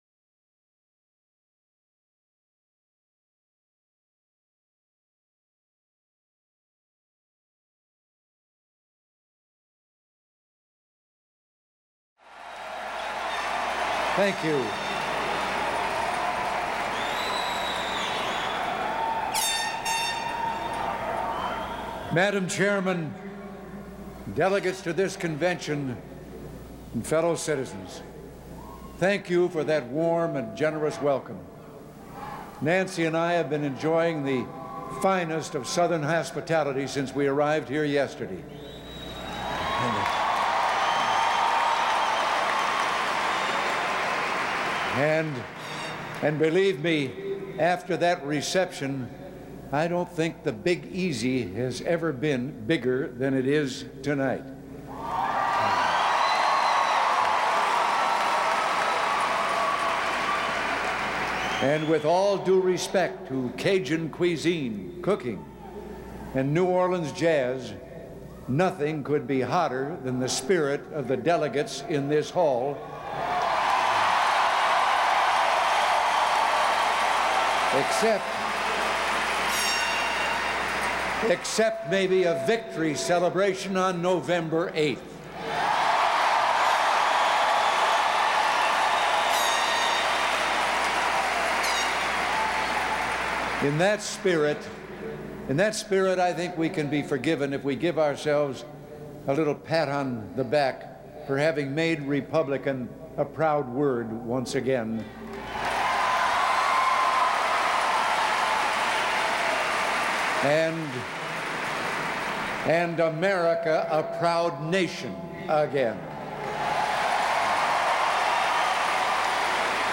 August 15, 1988: Farewell Address at the Republican National Convention